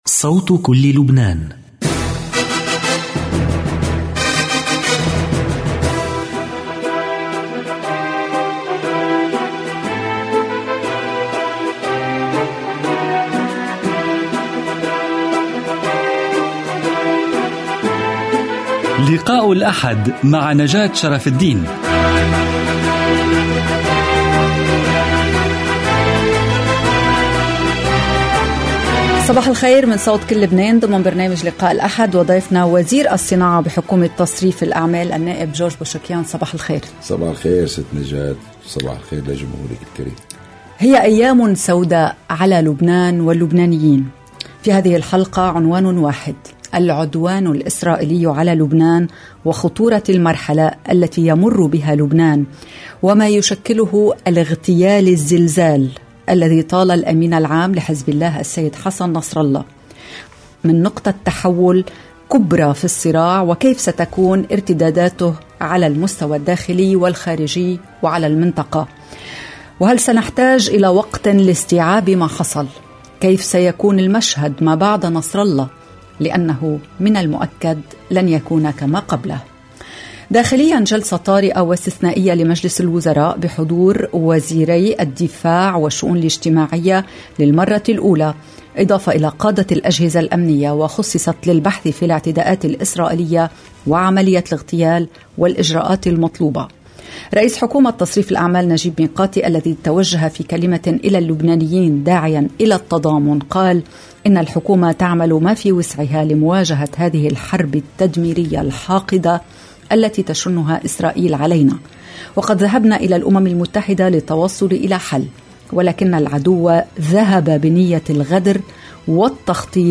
لقاء الأحد وزير الصناعة في حكومة تصريف الاعمال النائب جورج بوشيكيان Sep 29 2024 | 00:56:03 1x 00:00 / 00:56:03 Subscribe Share RSS Feed Share Link Embed